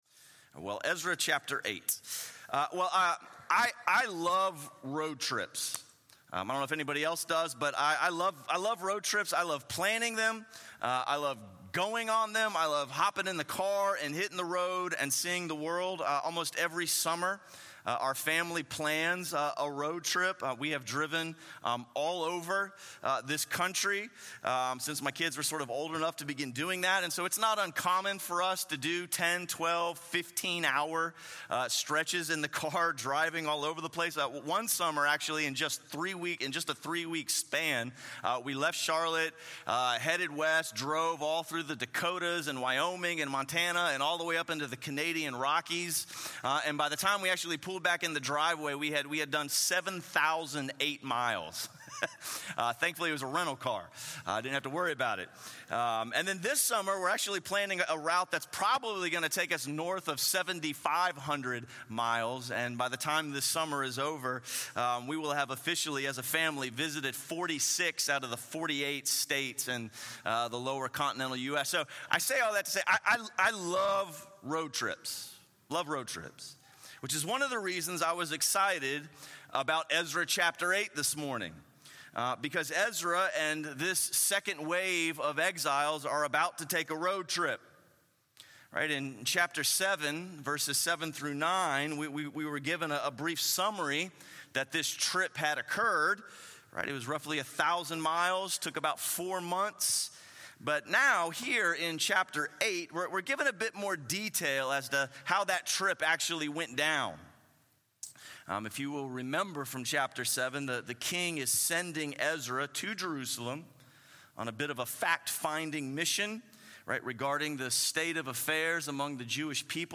A sermon series through the books of Ezra and Nehemiah.